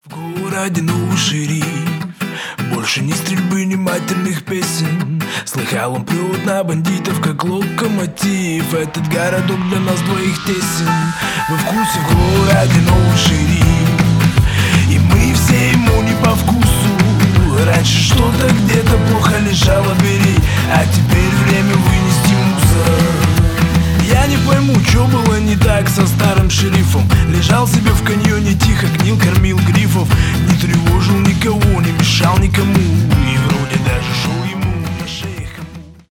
рэп , хип-хоп , кантри